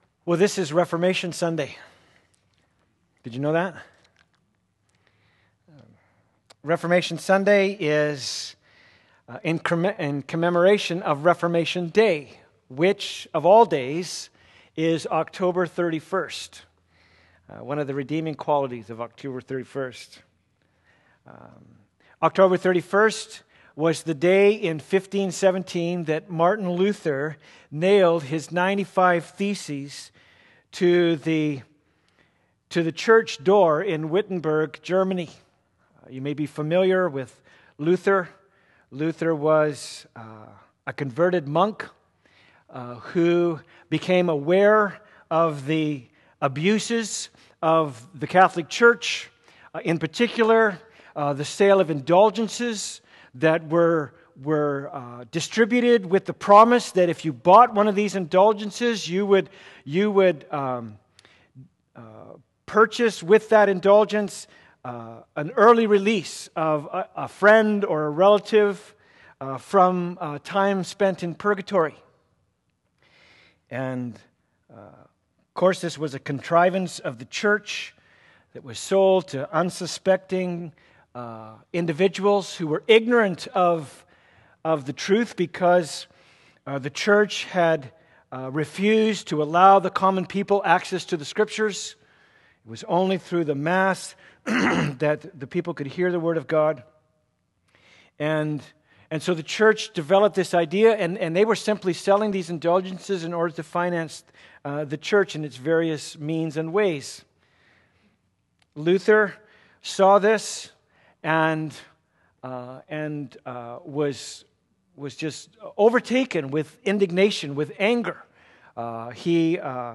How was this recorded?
Passage: Romans 1:16-17 Service Type: Sunday Service « Guest Speaker